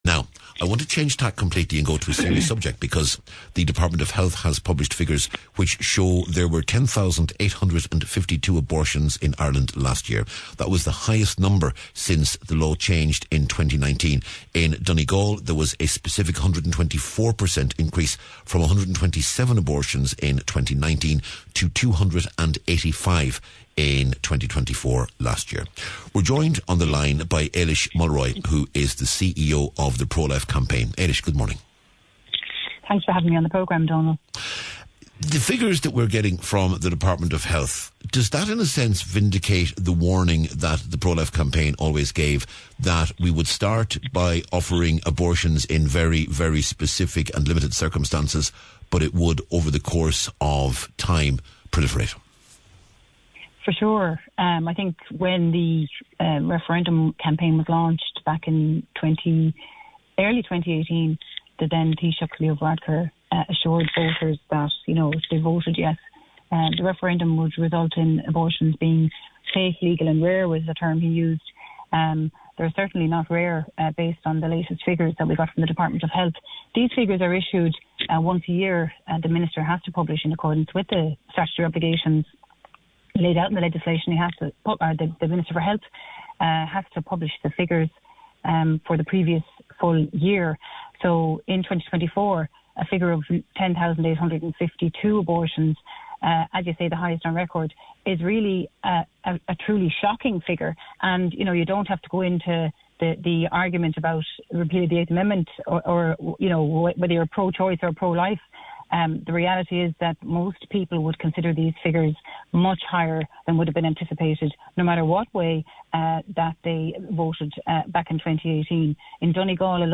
You spoke very clearly, concisely and to the point.